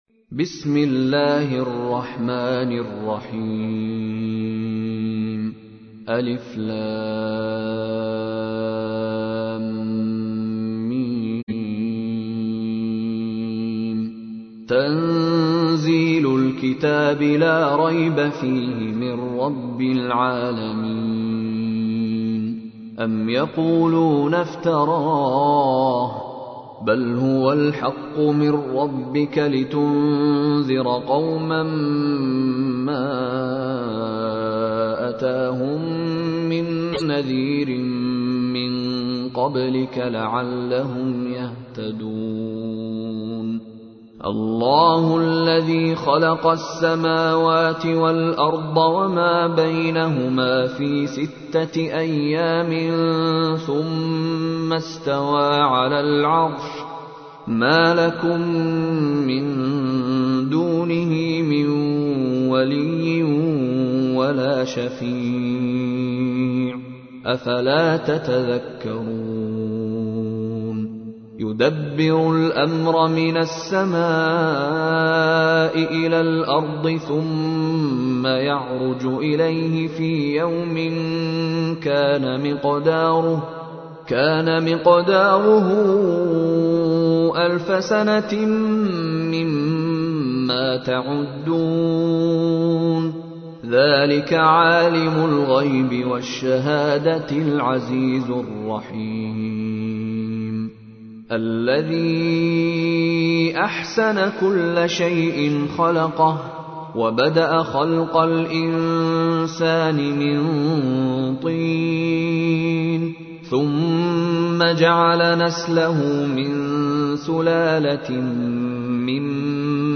تحميل : 32. سورة السجدة / القارئ مشاري راشد العفاسي / القرآن الكريم / موقع يا حسين